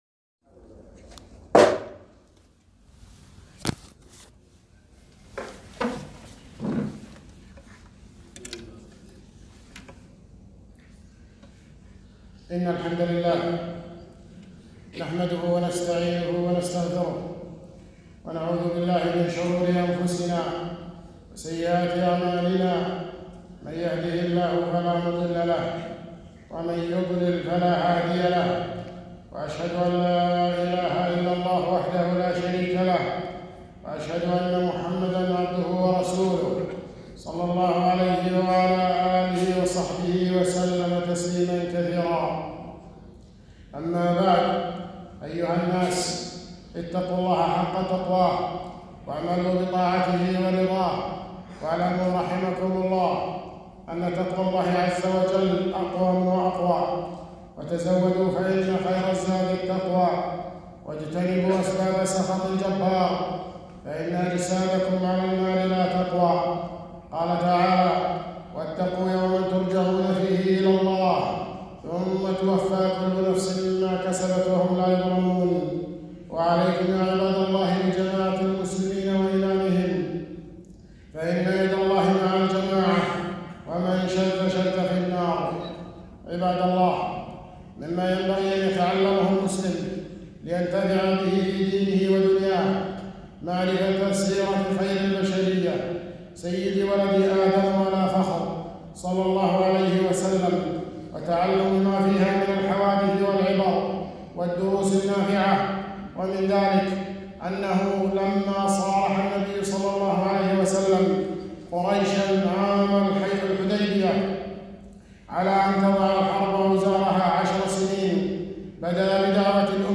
خطبة - فوائد من حديث هرقل مع أبي سفيان رضي الله عنه